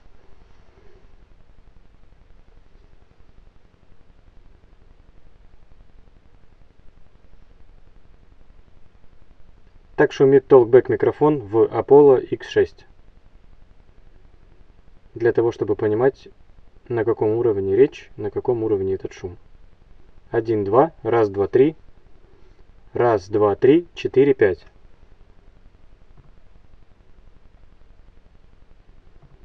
UAD Apollo X6 Шум Talkback микрофона
Пульсирующий шум, когда включаешь толкбек.
Файл в прикрепе, но еще ссылочка на ядиск на всякий. p.s. в файле я начинаю говорить, не делайте с самого начала слишком громко) Вложения ApolloX6_talkback.wav ApolloX6_talkback.wav 4 MB · Просмотры: 182